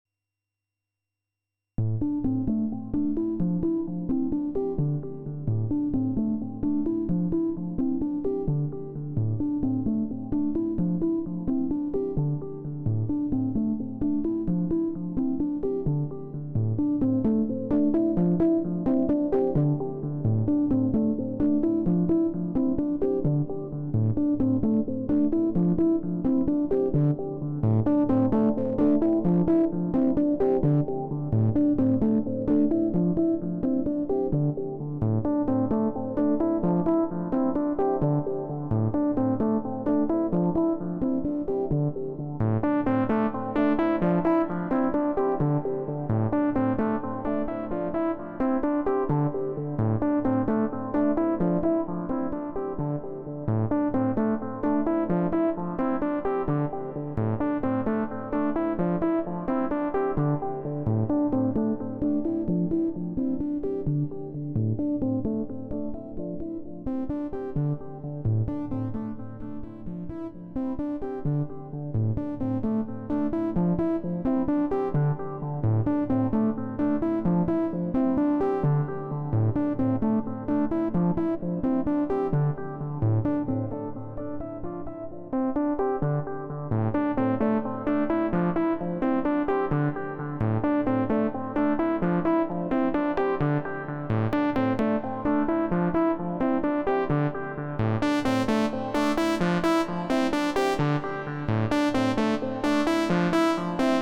Kobol Expander & SQ-64. Just chilling and looking for good patches for melodic techno (I definitely got a few already). Sorry for the distortion !